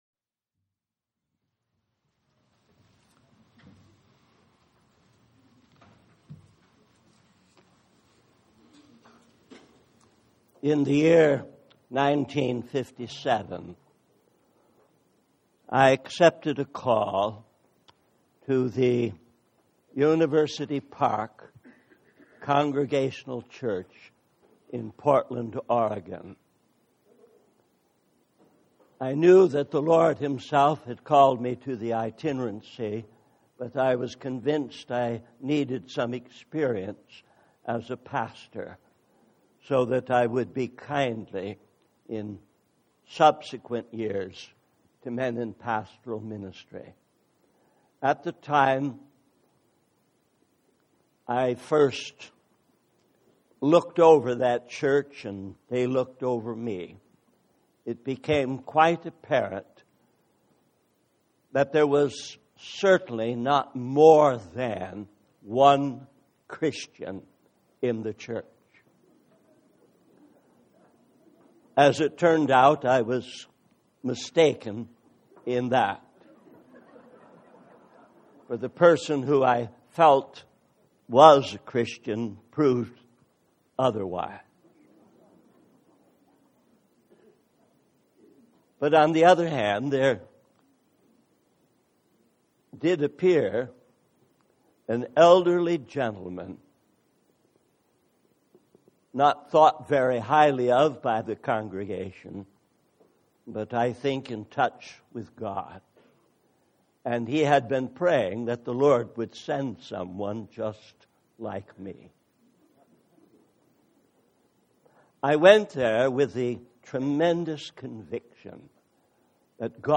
In this sermon, the speaker focuses on the book of Judges, specifically chapters 2 through 16. The main theme is the cycle of sin, judgment, repentance, and restoration that the Israelites go through repeatedly. The speaker emphasizes the importance of understanding and acknowledging the judgments of God as a means of returning to a right relationship with Him.